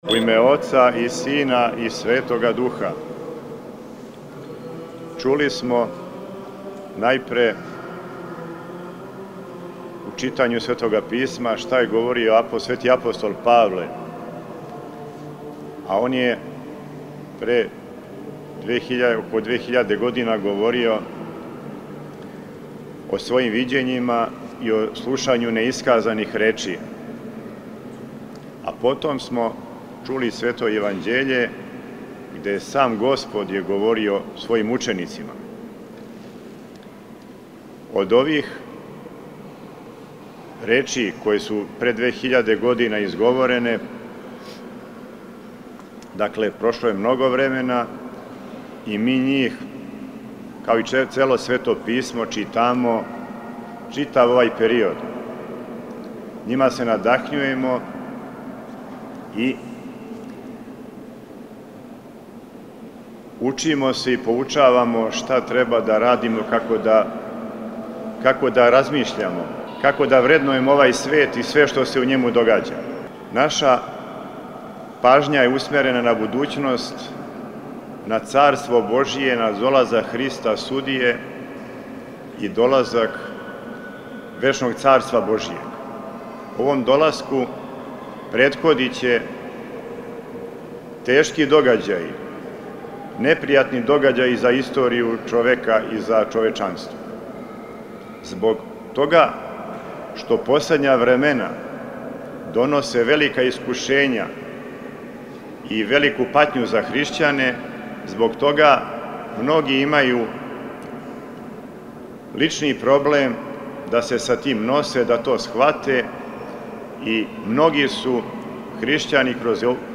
После прочитаног јеванђељског зачала, Епископ Тихон се обратио сабраном верном народу и, између осталог, рекао:
Послушајте у целости звучни запис беседе Његовог Преосвештенства Епископа моравичког г. Тихона, викара Патријарха српског:
Беседа Епископа моравичког г. Тихона у Храму Светог Саве на Врачару.mp3